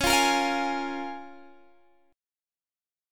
Listen to Db6 strummed